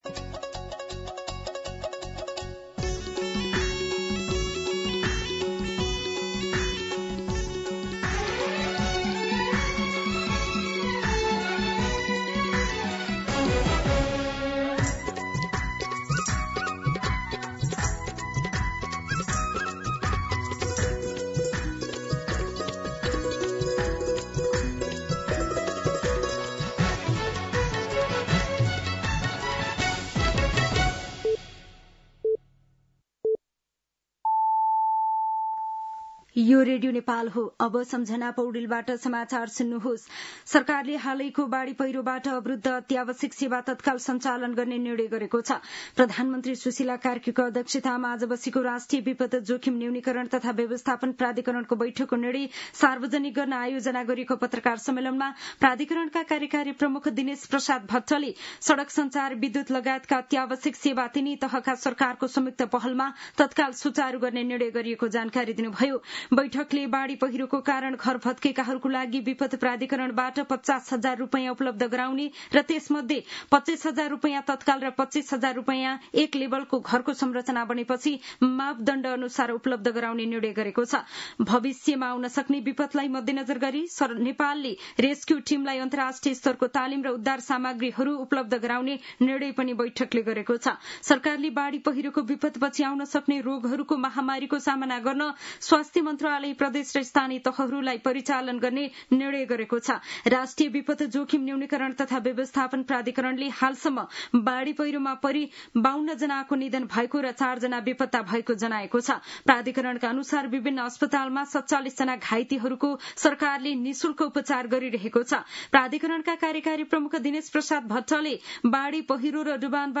दिउँसो ४ बजेको नेपाली समाचार : २१ असोज , २०८२
4pm-News-06-21.mp3